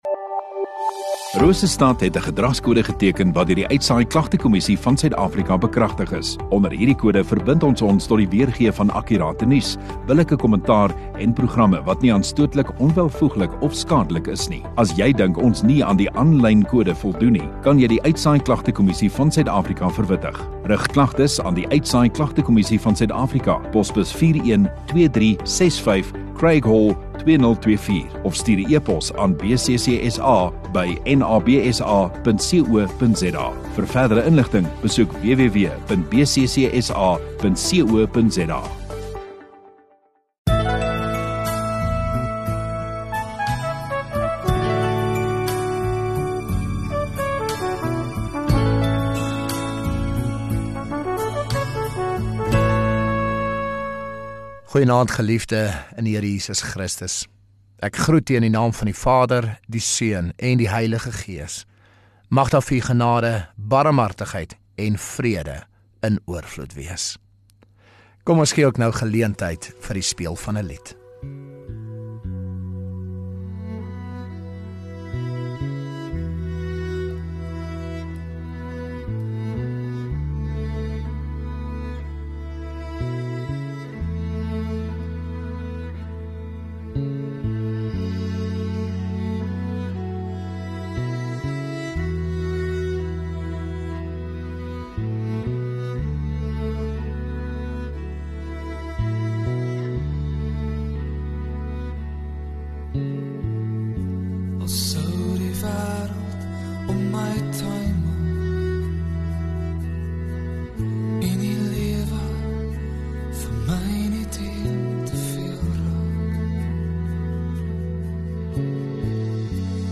9 Jun Sondagaand Erediens